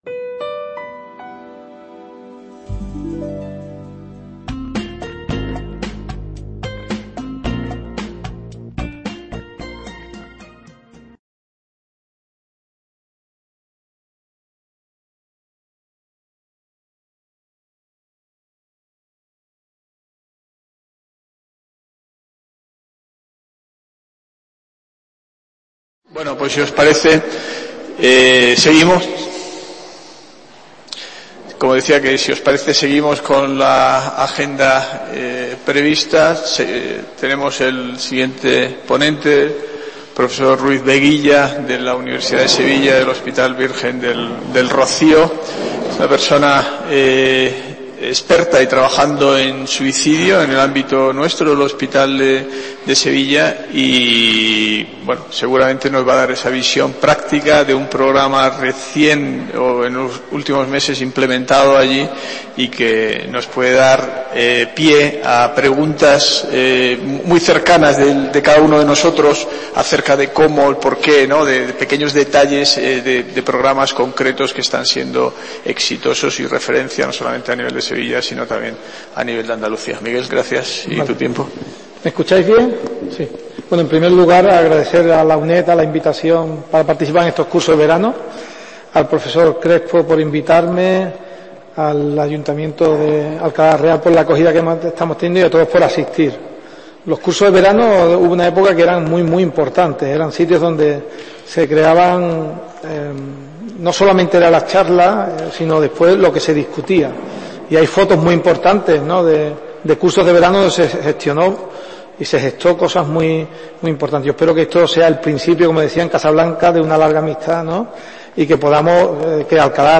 imparte la cuarta ponencia dentro del curso STOP SUICIDIO: UNA OPORTUNIDAD PARA VIVIR que se celebra en Alcalá la Real (5 a 7 de julio de 2021) en los Cursos de Verano de la UNED.